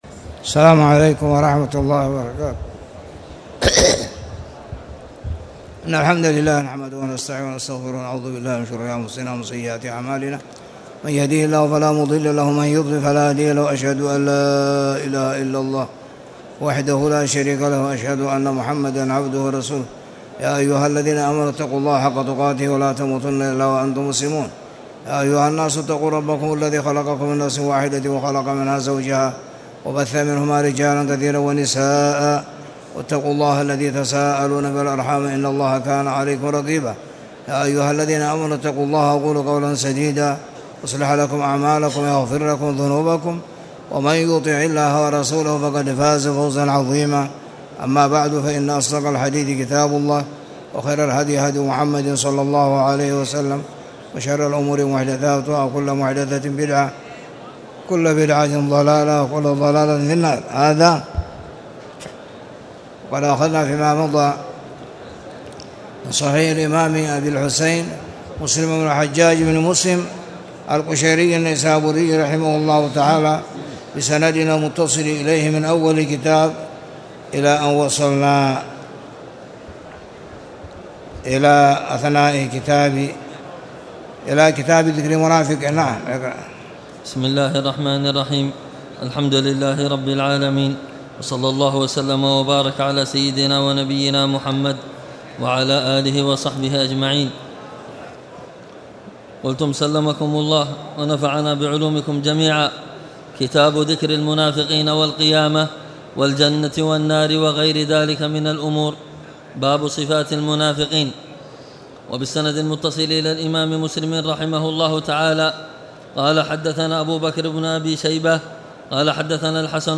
تاريخ النشر ١٣ رجب ١٤٣٨ هـ المكان: المسجد الحرام الشيخ